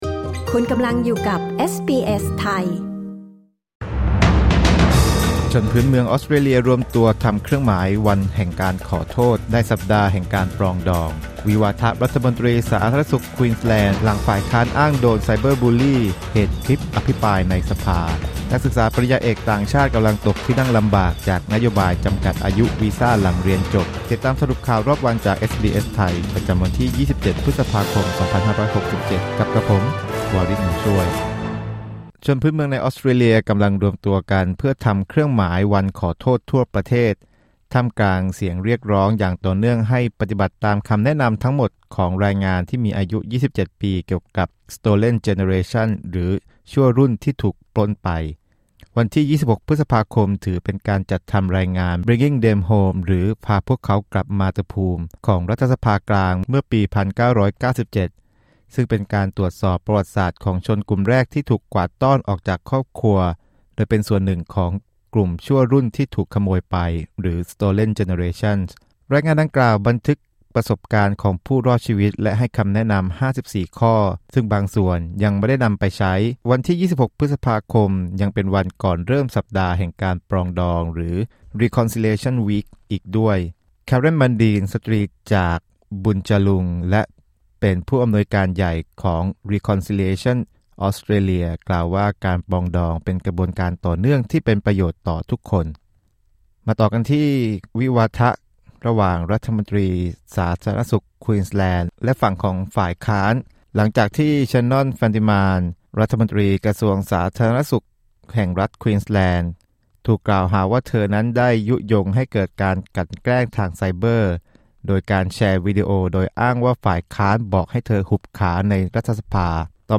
สรุปข่าวรอบวัน 27 พฤษภาคม 2567
คลิก ▶ ด้านบนเพื่อฟังรายงานข่าว